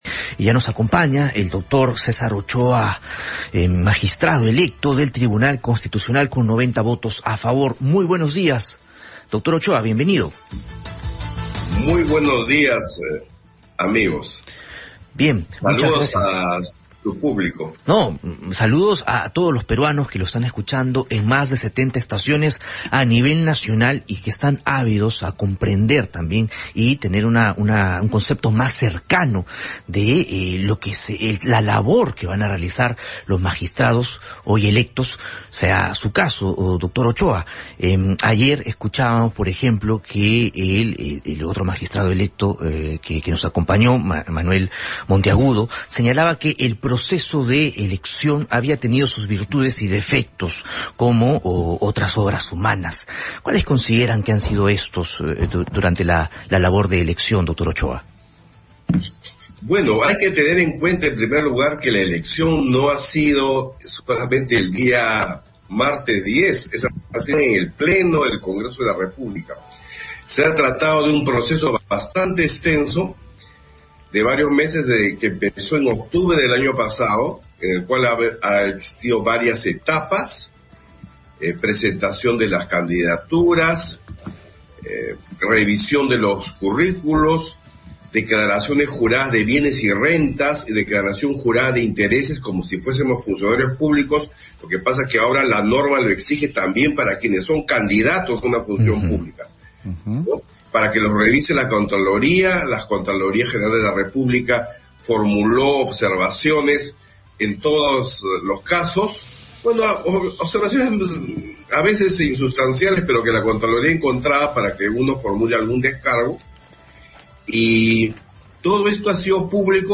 Enlace con César Ochoa, magistrado electo de Tribunal Constitucional